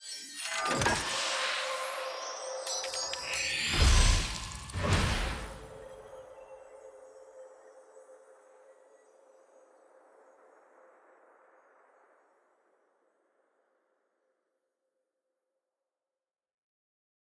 sfx-clash-capsule-tier-1-ante-1.ogg